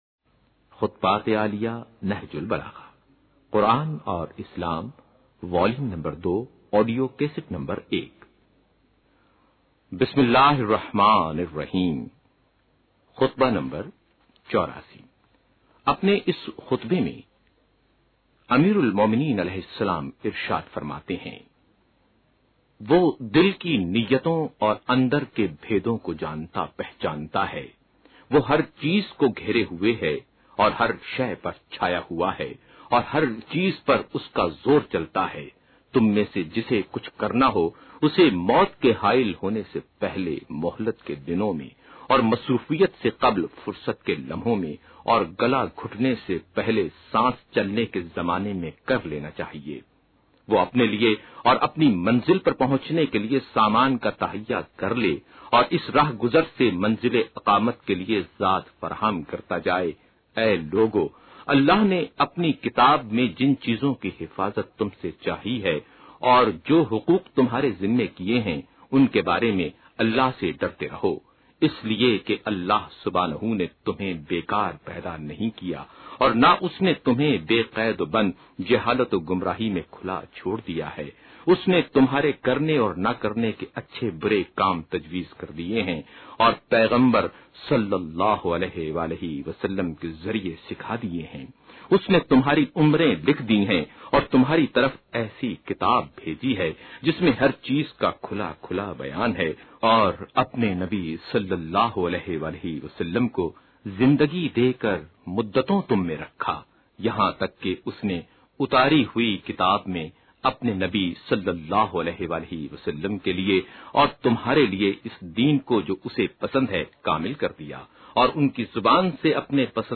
خطبہ نمبر84